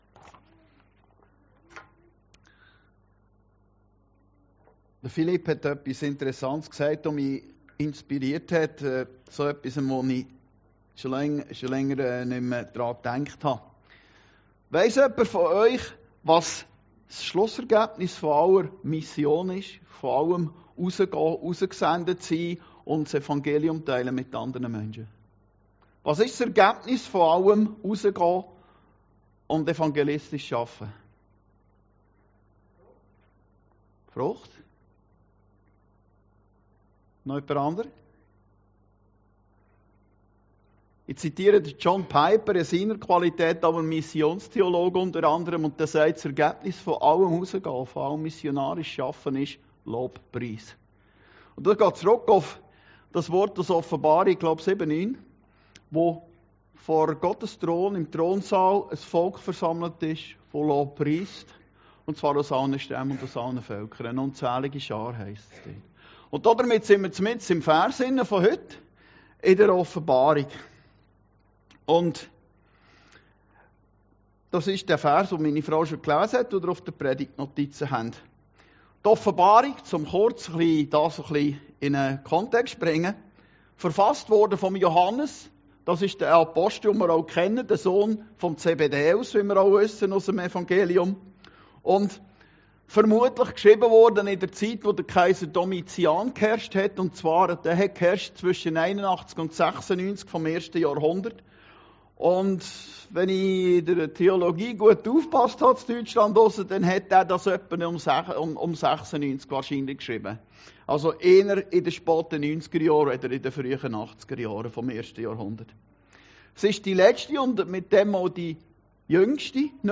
Predigten Heilsarmee Aargau Süd – Siehe ich mache alles neu!